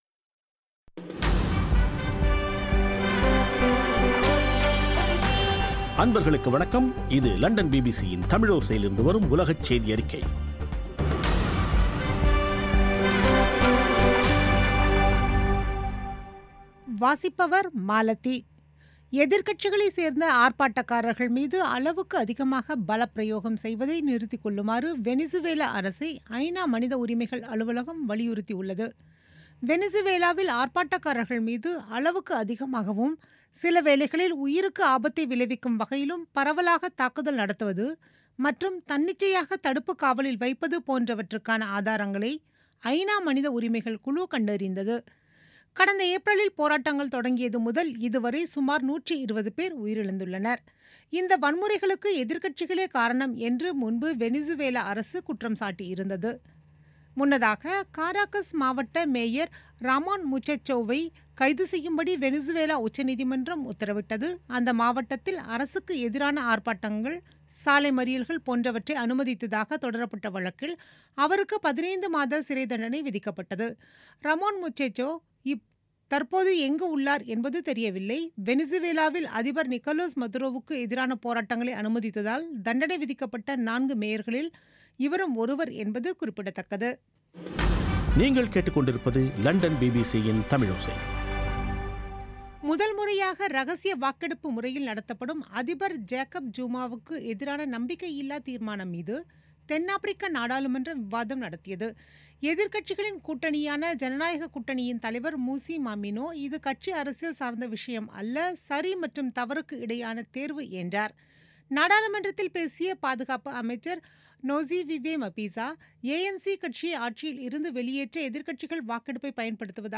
பிபிசி தமிழோசை செய்தியறிக்கை (08/08/2017)